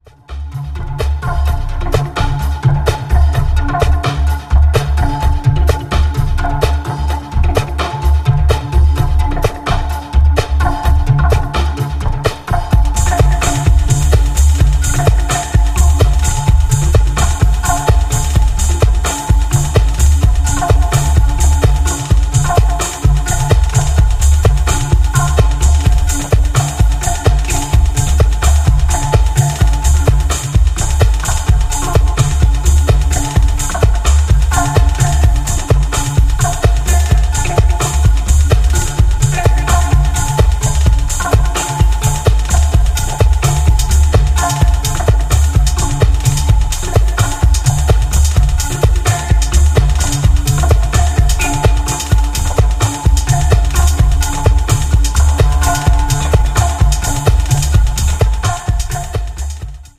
ダビーに跳ねるシンセ/スネアがファンキーな”A2”。